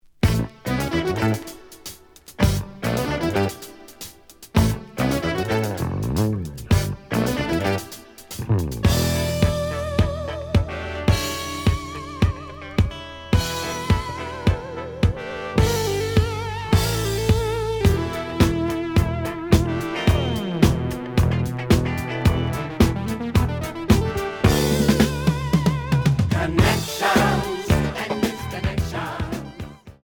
The audio sample is recorded from the actual item.
●Genre: Funk, 80's / 90's Funk